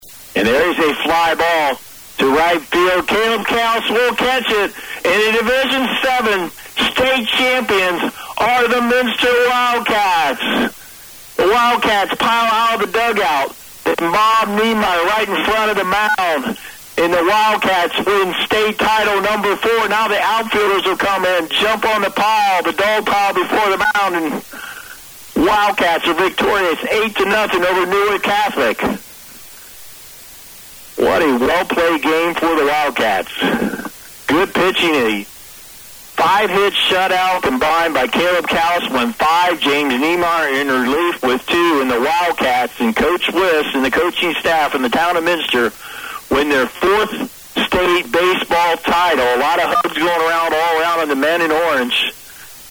AA00 END OF GAME.mp3